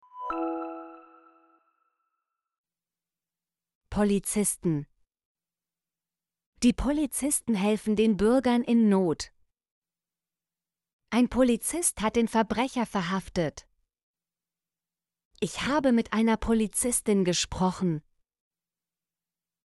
polizisten - Example Sentences & Pronunciation, German Frequency List